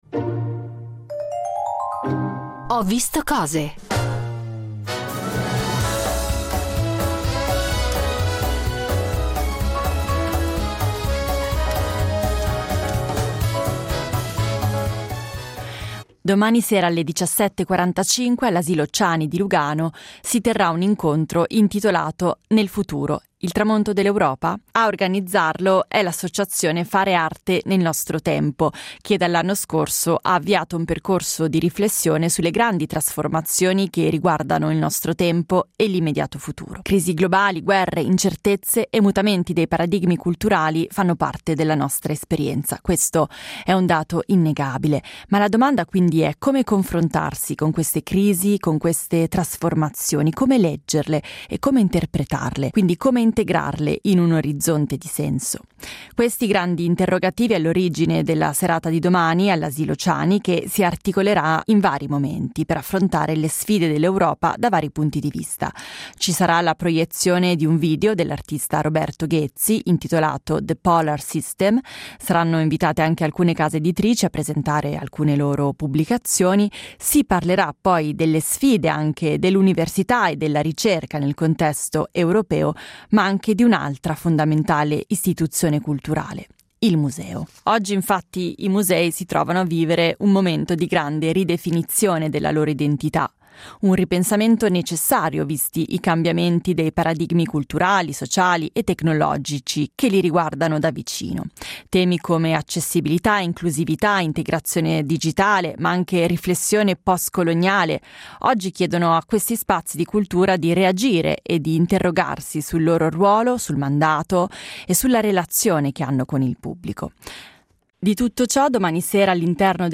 Abbiamo parlato con un relatore dell’evento